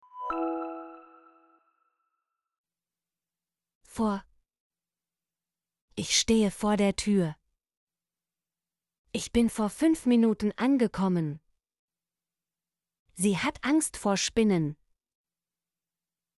vor - Example Sentences & Pronunciation, German Frequency List